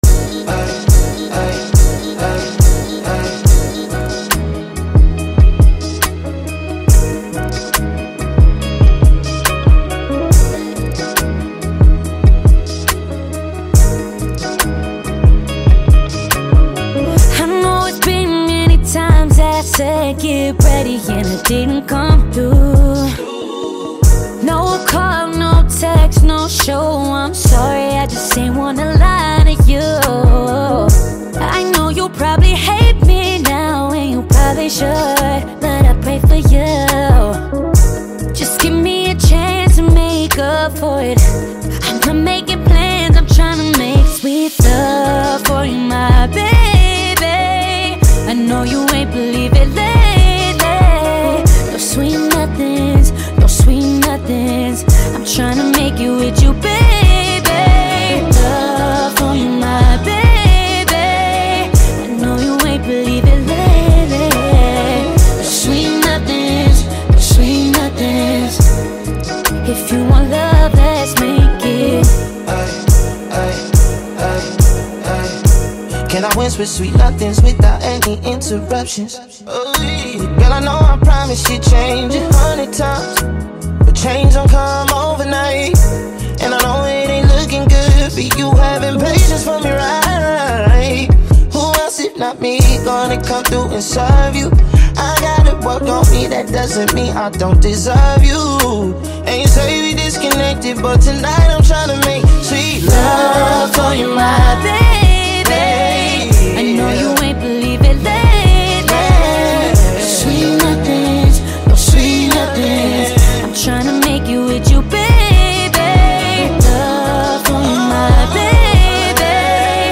It delivers a sound that feels both fresh and consistent.